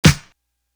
Hoagie Clap Snare.wav